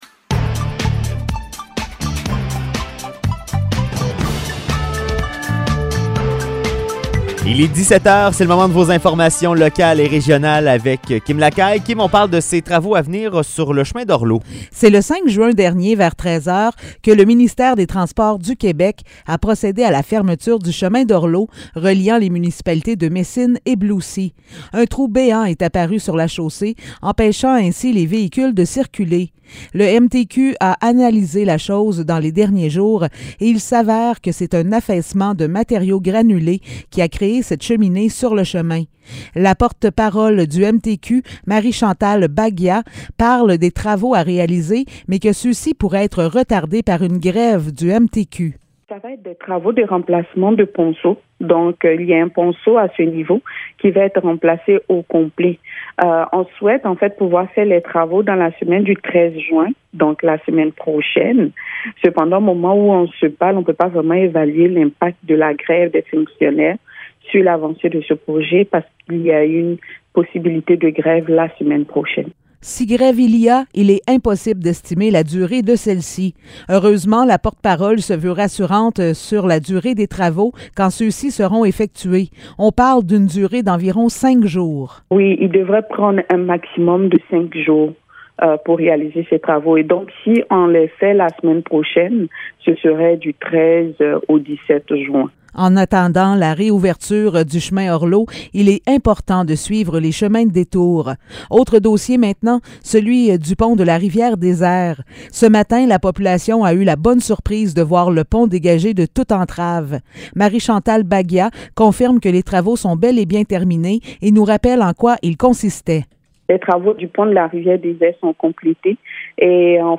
Nouvelles locales - 9 juin 2022 - 17 h